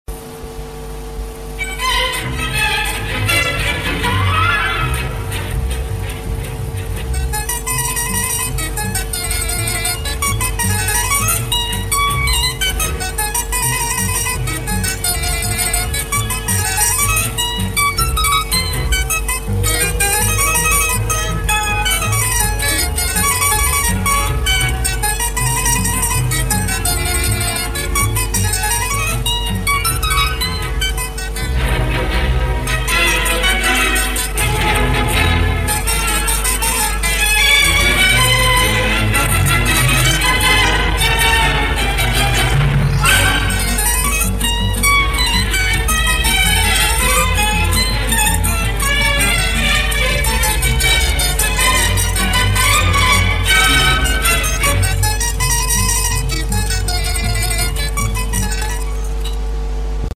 Напоминает какую-то польку или галоп одного из Штраусов.